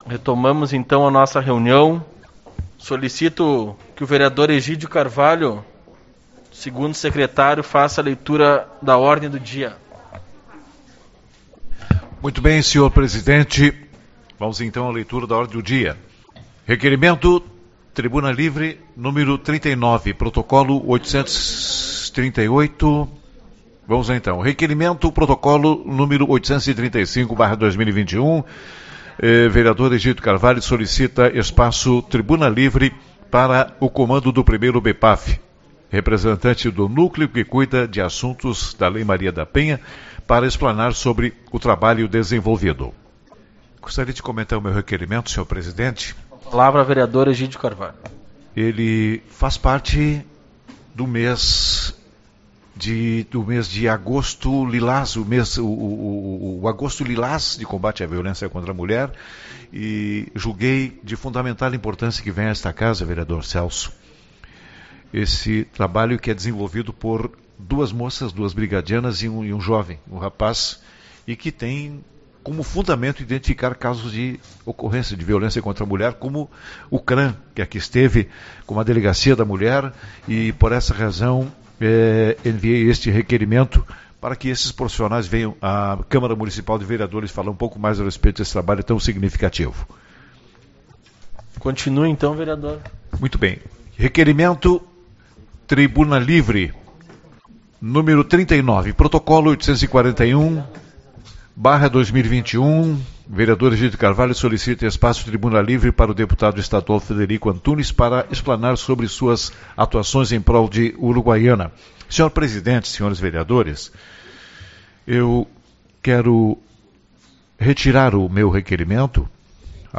Reunião Ordinária